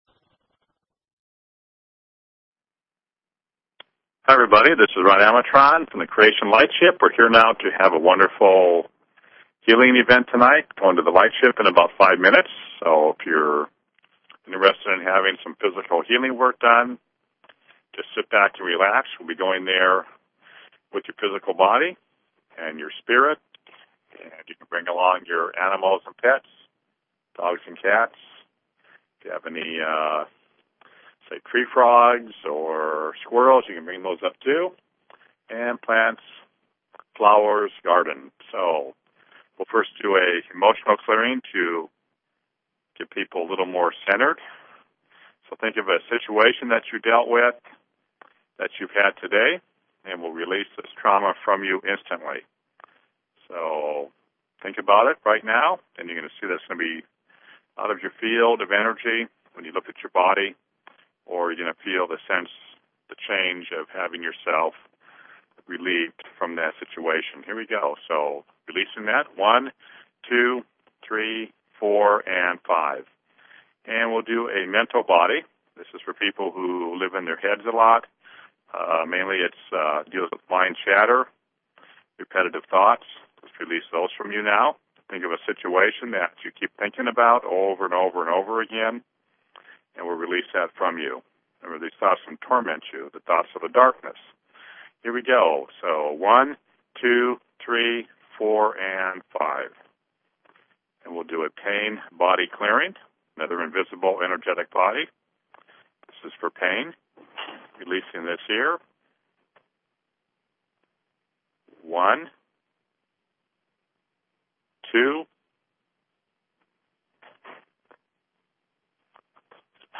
Talk Show Episode, Audio Podcast, Creation_Lightship_Healings and Courtesy of BBS Radio on , show guests , about , categorized as